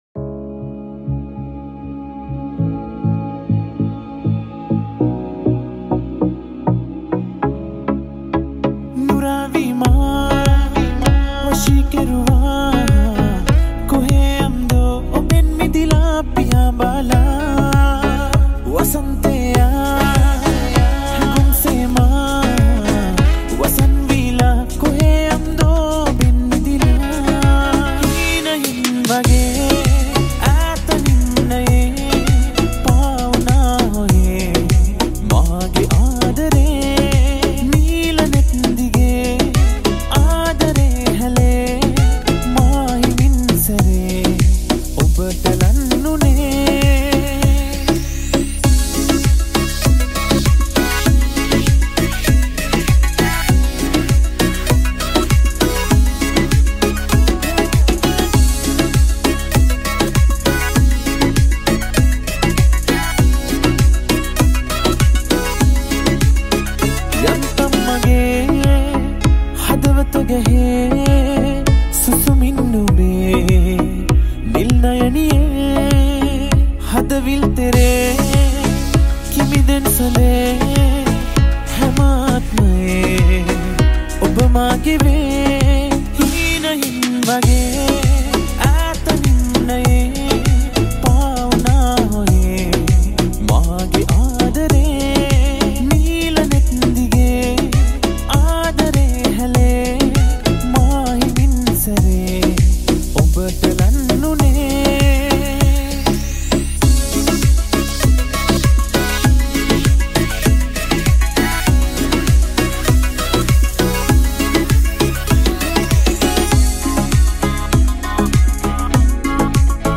Vocal
Guitar